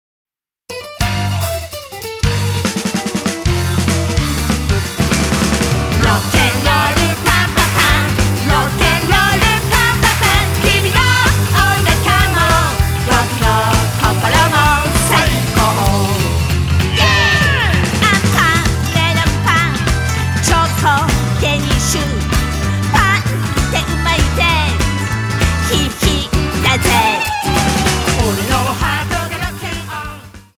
たいそう